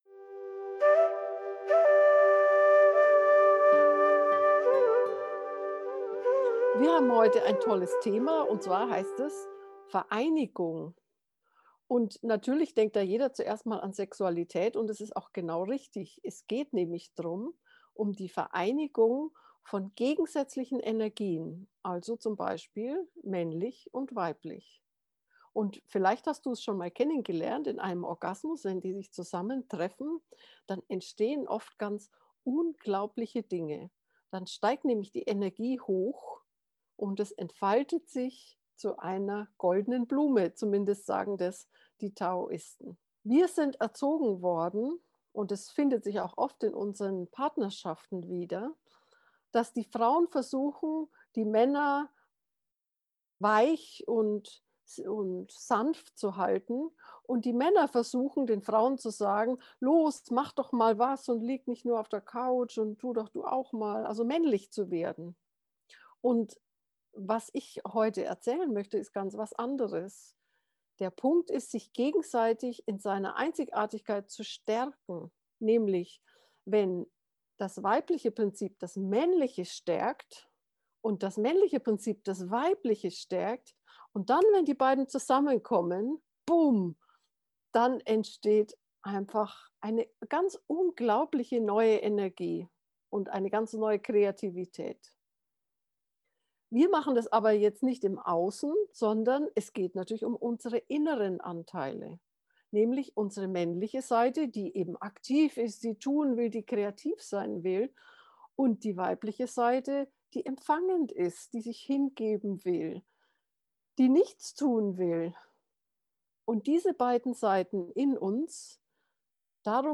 vereinigung-gegensaetze-gefuehrte-meditation